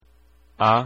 Давайте прослушаем произношение этих звуков:
á (открытое “a”) -